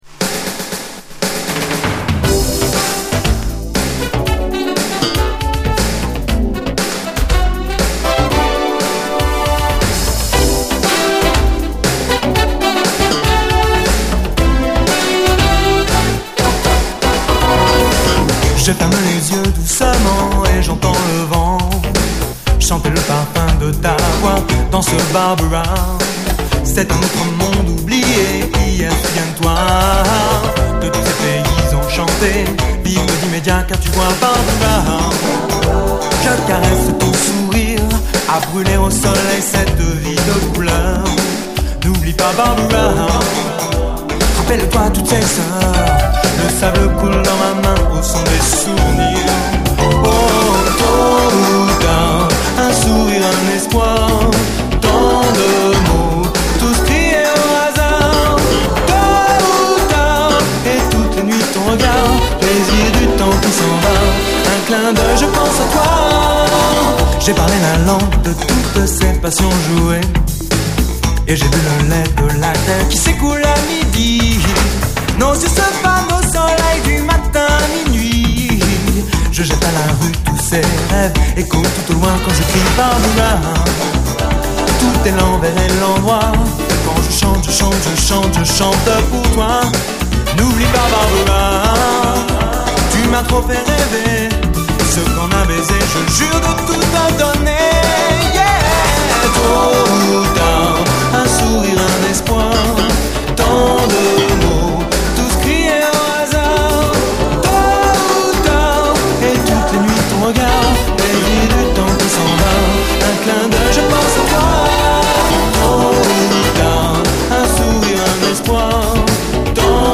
SOUL, 70's～ SOUL, DISCO, HIPHOP
ディスコ・ラップ、フェイク・ジャズ・ボッサな演奏も加わってしまった３ミックスがそれぞれ最高！